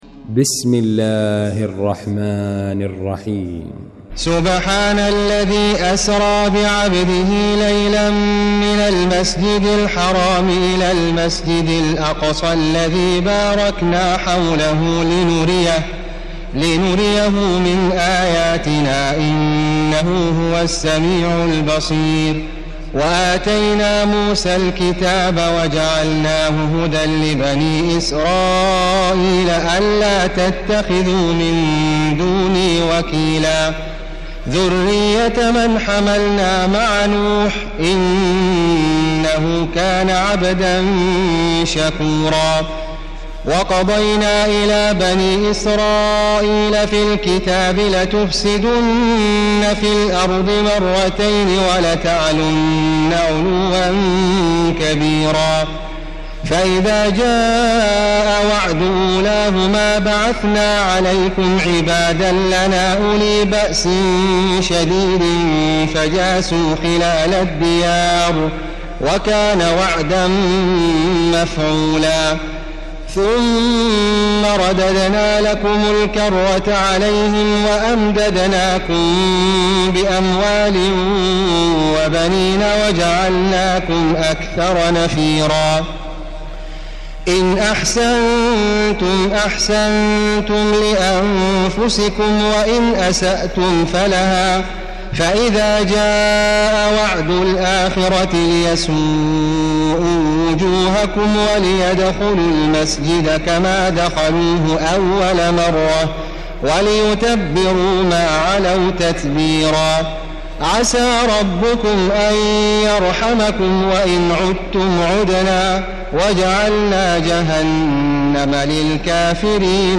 المكان: المسجد الحرام الشيخ: معالي الشيخ أ.د. بندر بليلة معالي الشيخ أ.د. بندر بليلة خالد الغامدي الإسراء